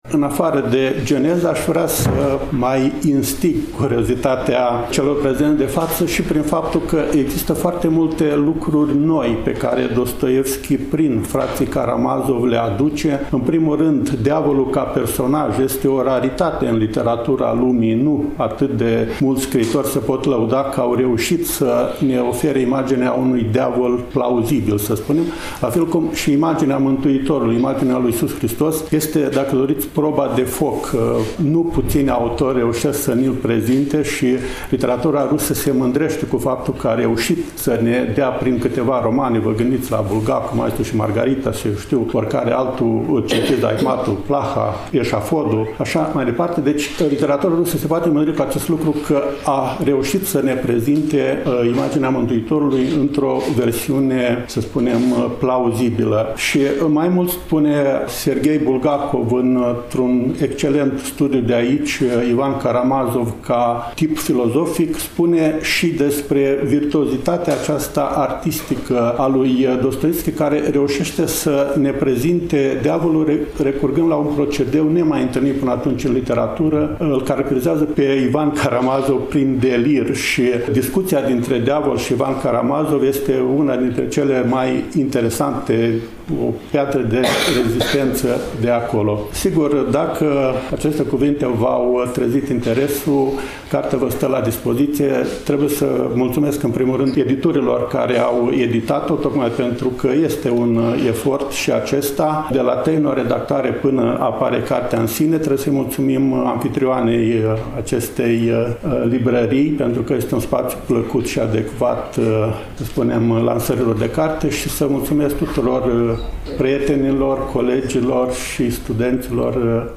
În ediția de astăzi a emisiunii noastre, relatăm de la prezentarea cărții Marele Inchizitor. Dostoievski în interpretări teologico-filosofice (Konstantin Leontiev, Vladimir Soloviov, Vasili Rozanov, Serghei Bulgakov, Nikolai Berdiaev, Dmitri Merejkovski, Semion Frank, Nikolai Losski), eveniment desfășurat în ziua de joi, 14 noiembrie 2024, începând cu ora 14,  la Iași, în incinta Librăria Tafrali, corpul A al Universității „Alexandru Ioan Cuza”.